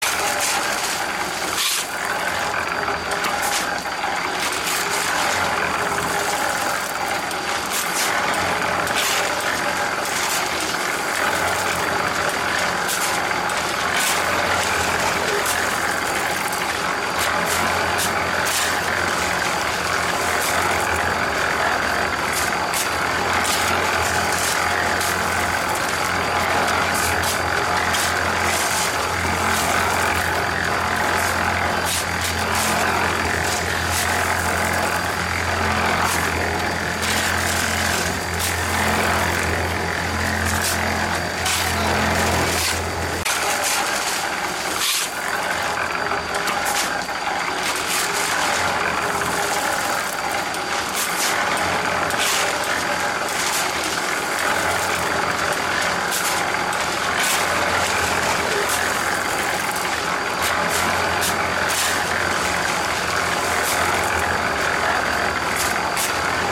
Tiếng Xúc cát sạn vào máy Trộn Hồ, Trộn Bê Tông, Xi măng…
Thể loại: Tiếng đồ vật
Description: Tiếng xúc cát sạn từ xẻng chạm vào, vang lên âm thanh lách cách, lạch cạch. Khi đổ cát sạn vào máy trộn hồ, trộn bê tông xi măng, phát ra những tiếng sột soạt, lạo xạo, rào rào, nghe rõ âm vang của vật liệu thô va vào thành sắt. Sau đó, tiếng máy trộn nổ máy, gầm nhẹ, rồi xoay vù vù, vòn vòn, tạo nên âm thanh cơ khí đặc trưng rù rù, cạch cạch, hòa trộn cùng tiếng vật liệu bị đảo đều bên trong.
tieng-xuc-cat-san-vao-may-tron-ho-tron-be-tong-www_tiengdong_com.mp3